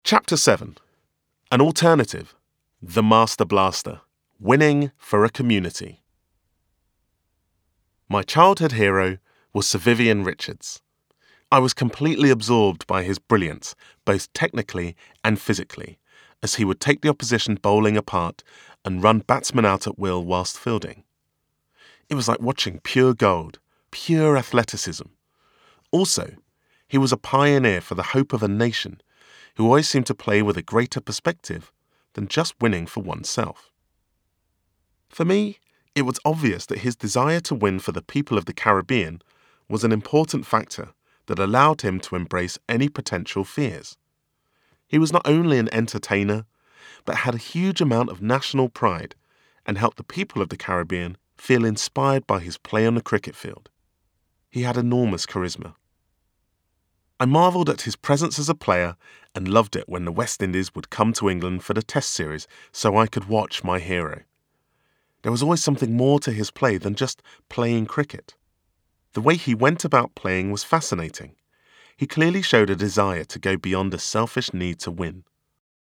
• Native Accent: London, RP, African